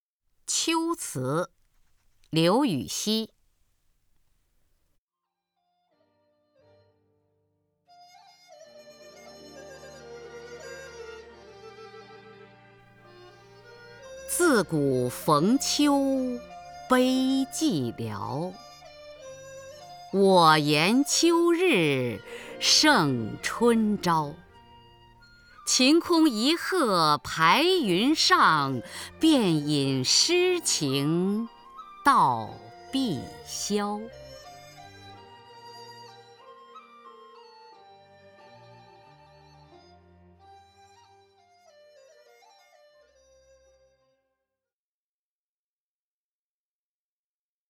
雅坤朗诵：《秋词·其一》(（唐）刘禹锡) （唐）刘禹锡 名家朗诵欣赏雅坤 语文PLUS